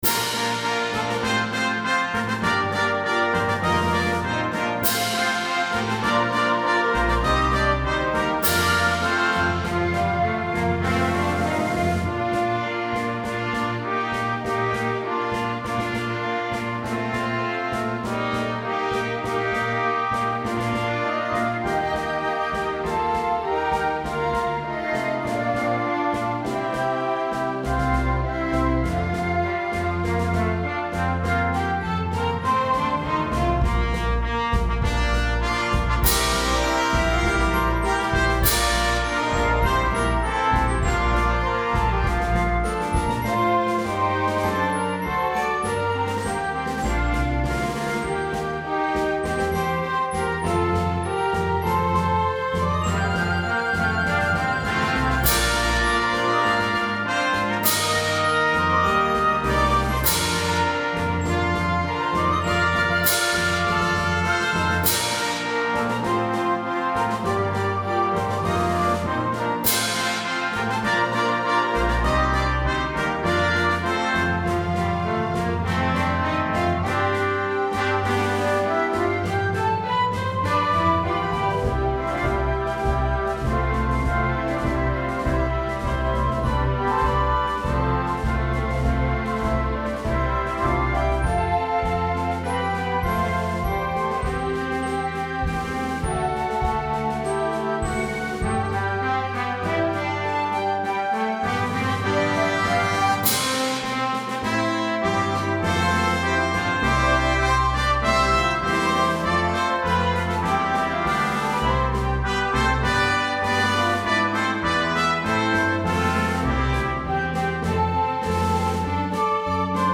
Hymn arrangement
for 9 Plus orchestra – with reduced instrumentation.